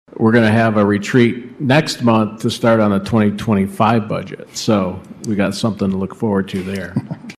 City Manager Jim Ritzema says budget preparation has become a year round activity.